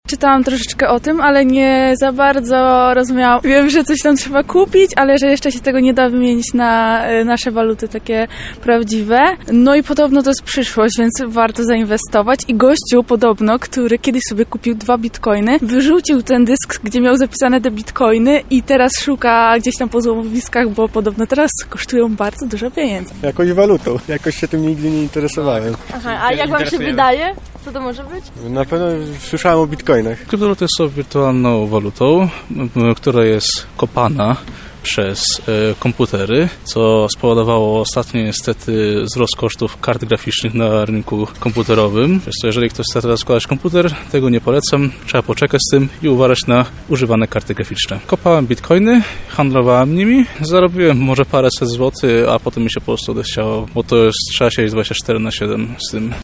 Zapytaliśmy też mieszkańców Lublina, czy wiedzą, czym jest kryptowaluta i co o niej sądzą.